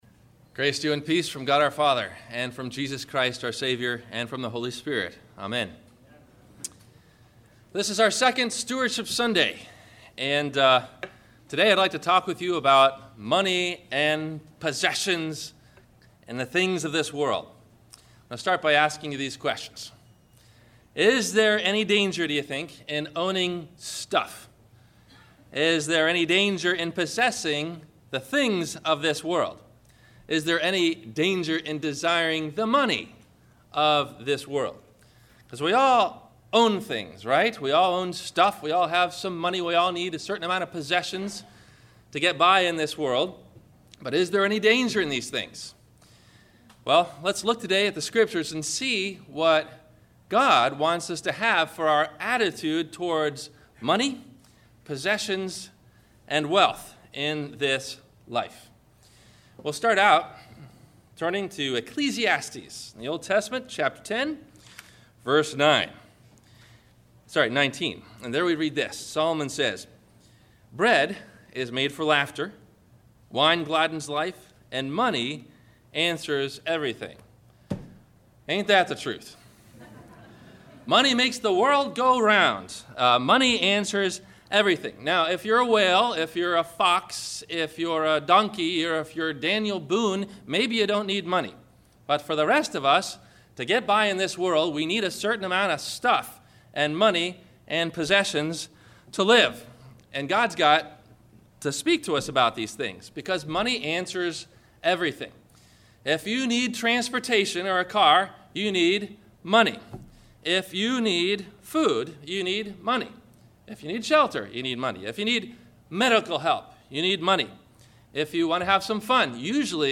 Can The Rich Man Be Saved? – Sermon – November 18 2012